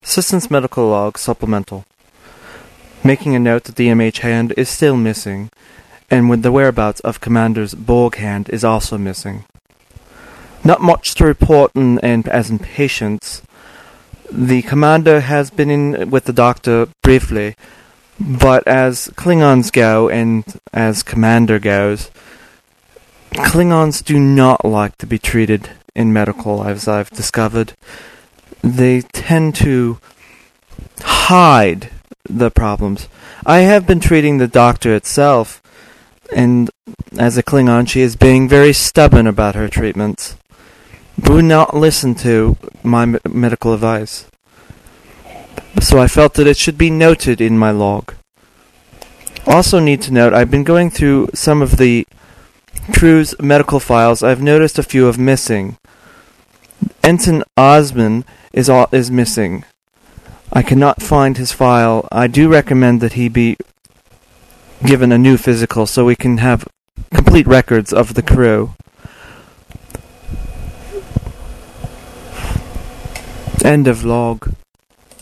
Here you will here some of the voice logs I did for the QOB.